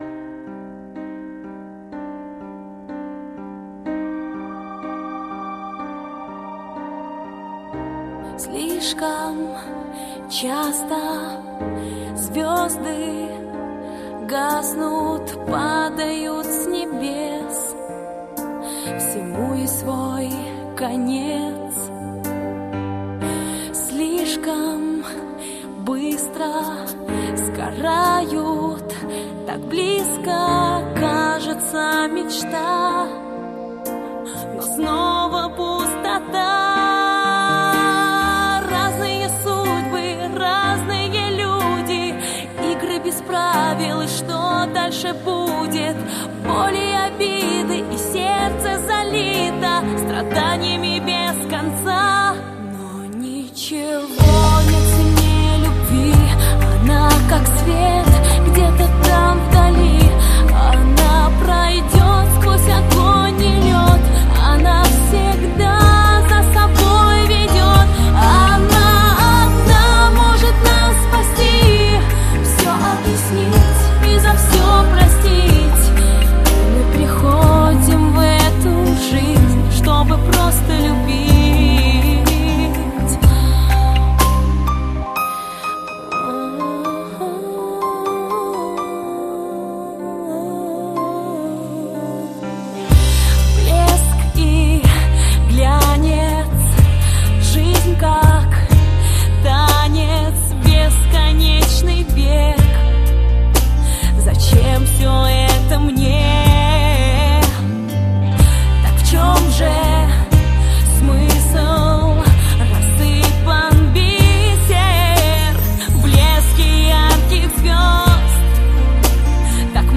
песня для школьного праздника